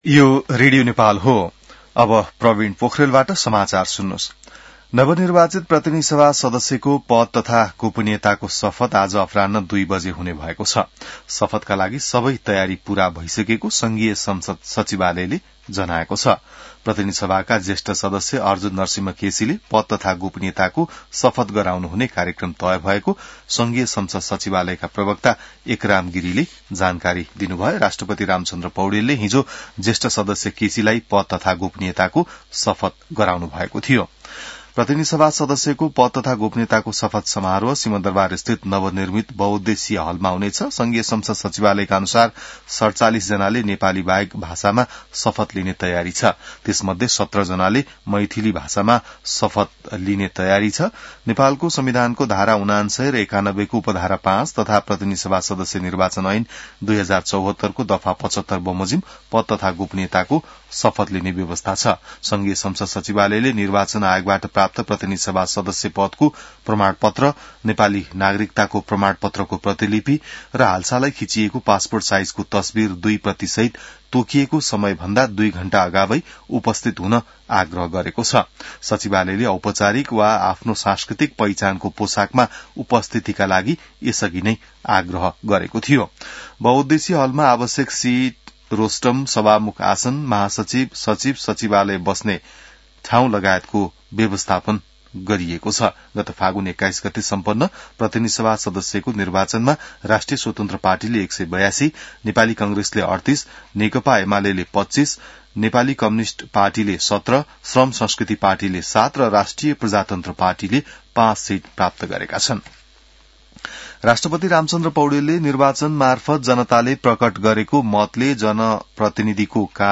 An online outlet of Nepal's national radio broadcaster
बिहान ६ बजेको नेपाली समाचार : १२ चैत , २०८२